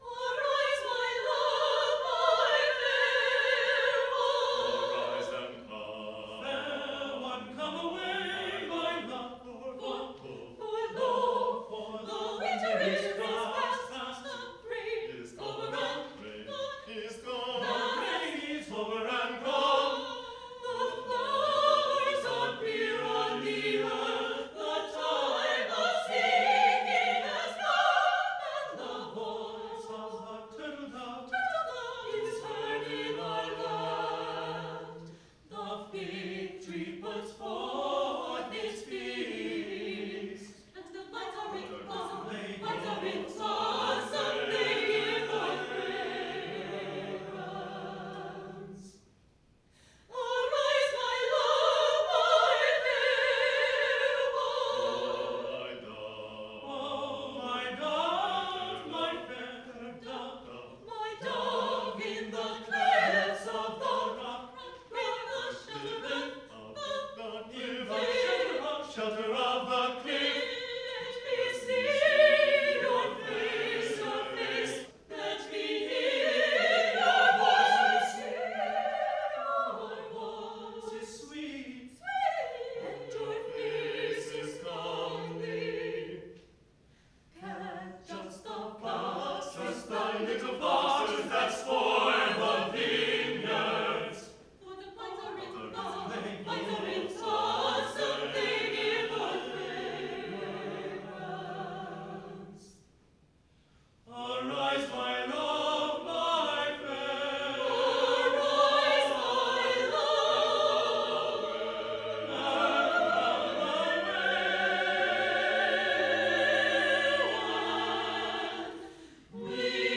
Vocal Works
This recording is a performance by a pick-up group that I occasionally put together (The Vocables) to do various pieces, including When All Paths Are Peace.